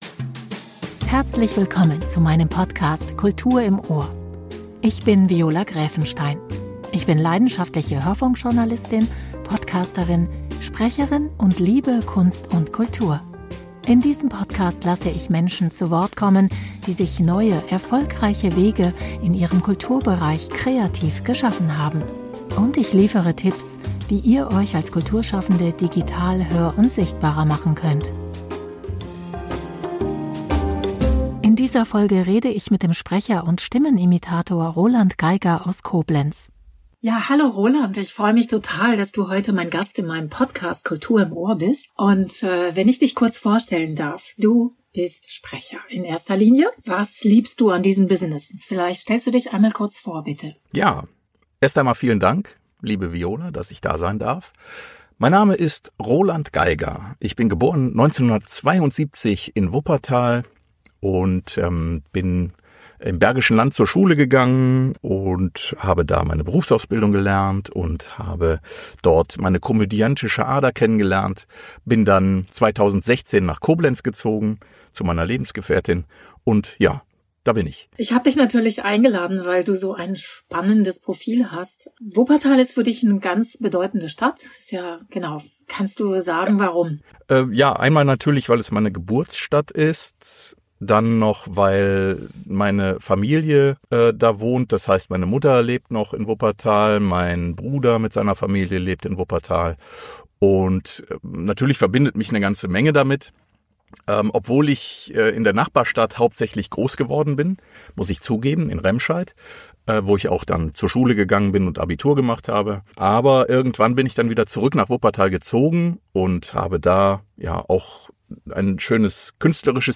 Dazu kommen Menschen aus Kunst und Kultur zu Wort, die ganz eigene Wege gehen und etwas zum Thema erfolgreiche Selbstvermarktung und Kreativität beitragen können.